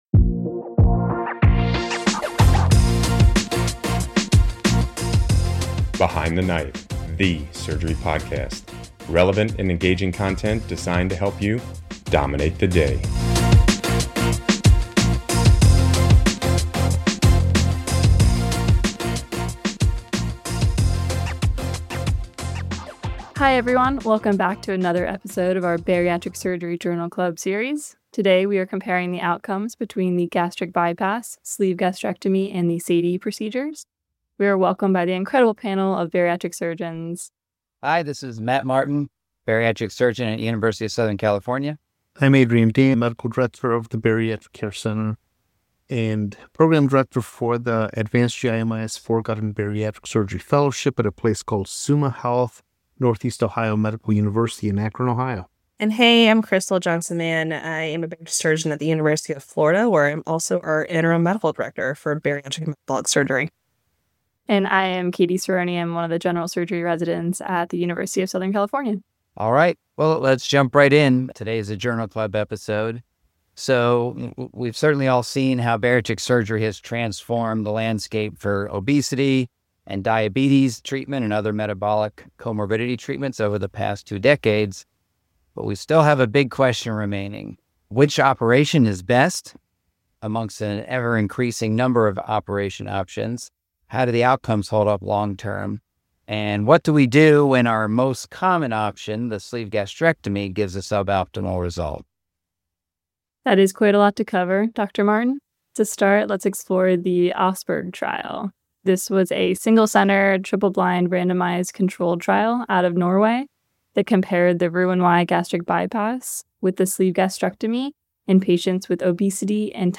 Sleeve, bypass, or something new – which surgery really dominates the day? This Bariatric Surgery Journal Club dives into the debate over which bariatric operation is best.